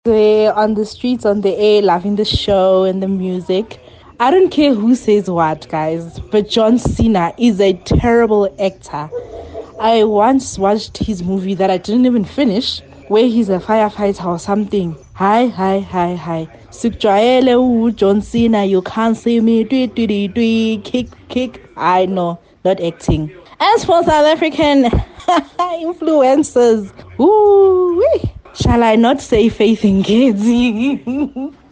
Kaya Drive listeners weighed in on the discussion here: